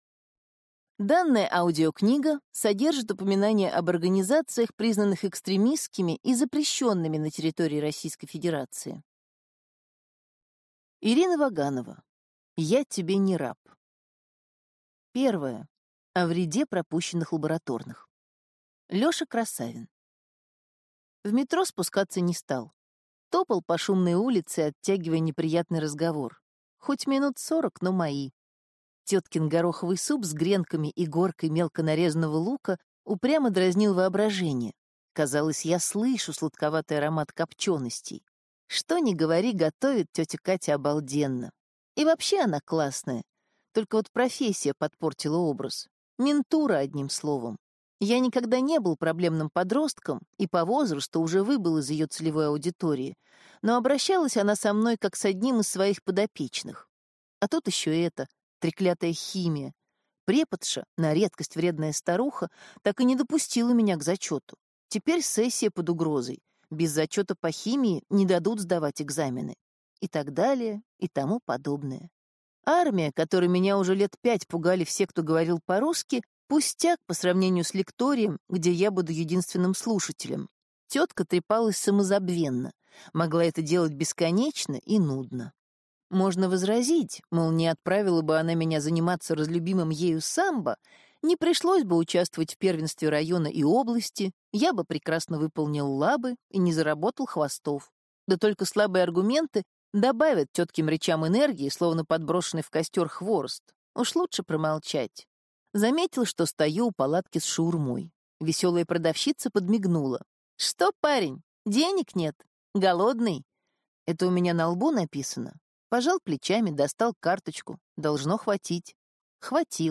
Аудиокнига Я тебе не раб | Библиотека аудиокниг